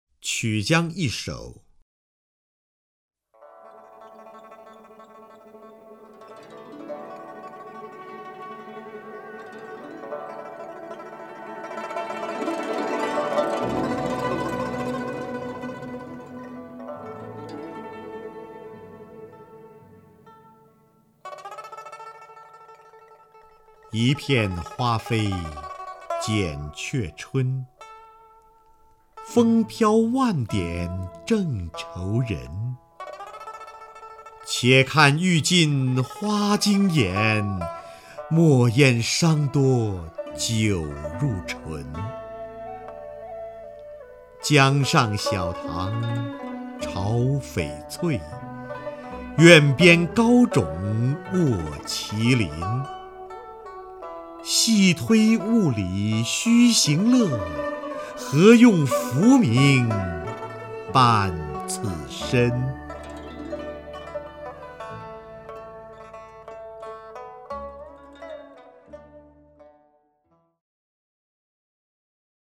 首页 视听 名家朗诵欣赏 瞿弦和
瞿弦和朗诵：《曲江二首·其一》(（唐）杜甫)